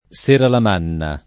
seralamanna [ S eralam # nna ]